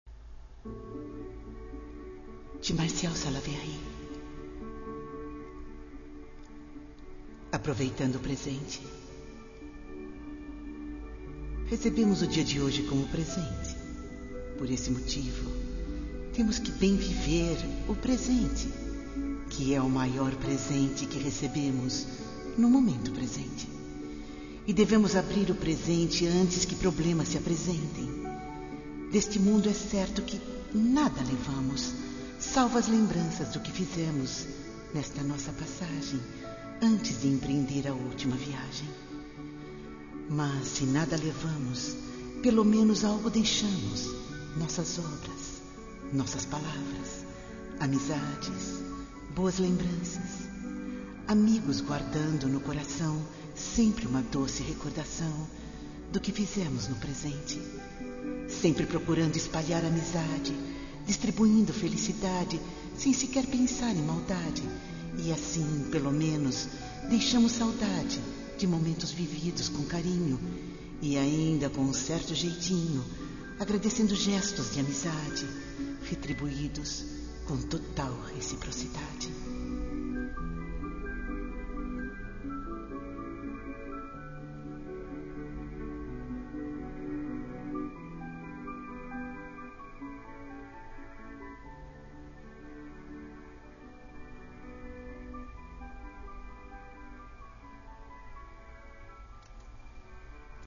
Se desejar ouvir o Fundo Musical após a Leitura do Texto,